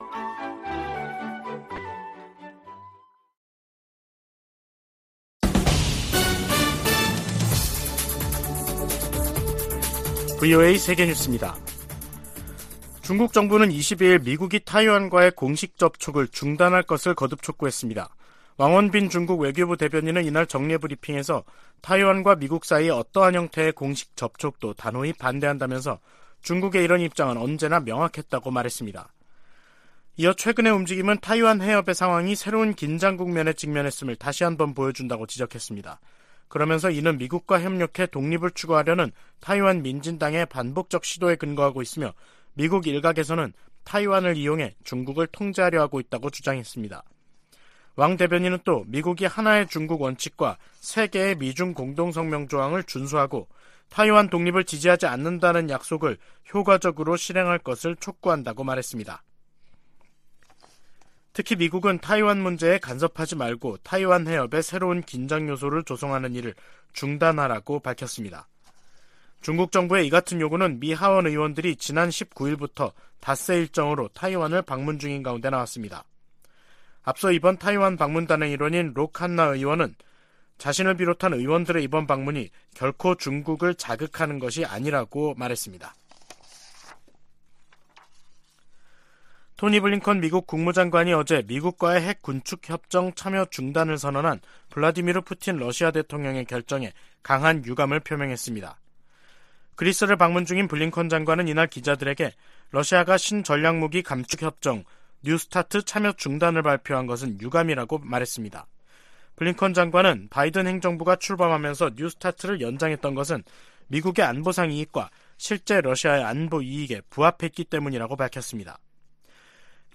VOA 한국어 간판 뉴스 프로그램 '뉴스 투데이', 2023년 2월 22일 3부 방송입니다. 미 하원 군사위원장이 북한의 미사일 위협에 맞서 본토 미사일 방어망을 서둘러 확충할 것을 바이든 행정부에 촉구했습니다.